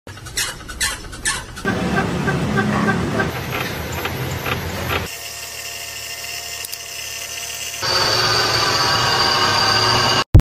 Car trouble sounds Part 4 sound effects free download